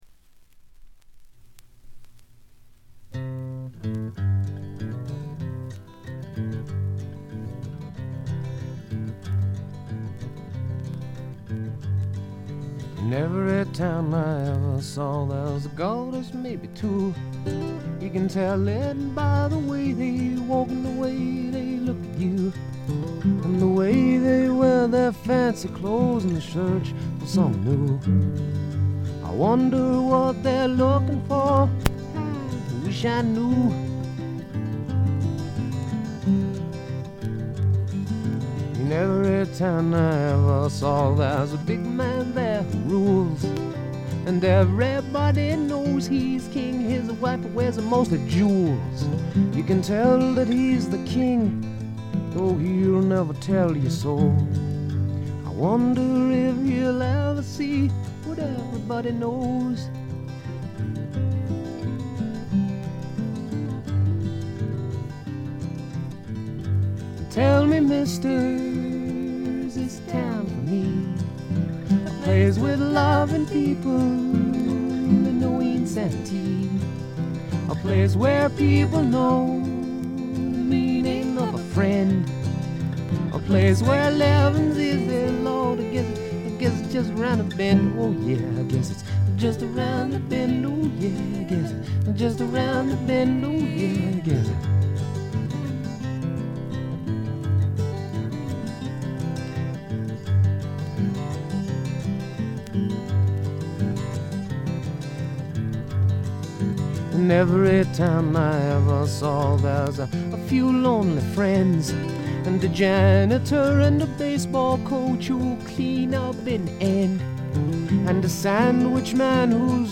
プレスのせいかチリプチ少々出ます。
本人のギター弾き語りを基本に友人たちによるごくシンプルなバックが付くだけのフォーキーな作品です。
とてもおだやかでドリーミーな感覚もがただようフォーク作品です。
試聴曲は現品からの取り込み音源です。
ちなみに試聴曲はA7以外は女性ヴォーカルとのデュエットです。
※序盤に大きなプツ音が入りますが静電気等のいたずらかと思います。
guitar
banjo
harmonica
violins
recorder
piano